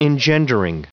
Prononciation du mot engendering en anglais (fichier audio)
Prononciation du mot : engendering